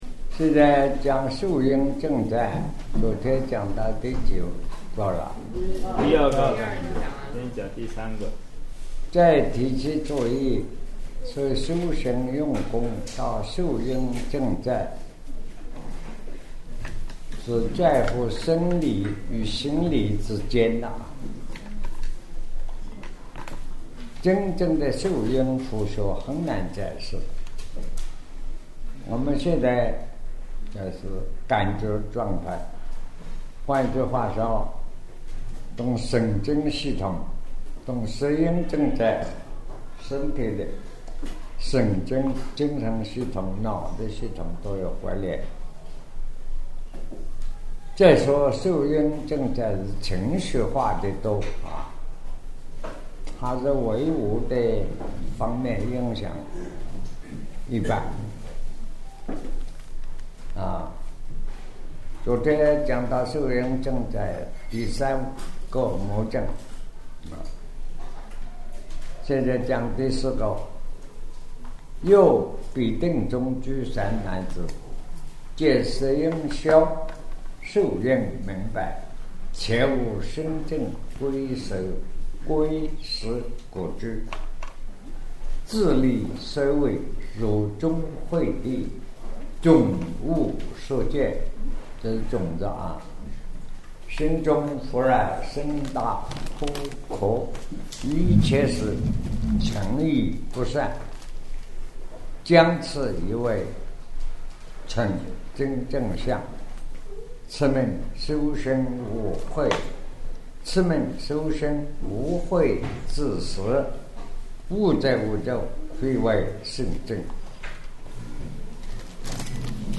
南怀瑾先生2009年讲楞严经142讲 卷九 五阴解脱 受阴区宇魔境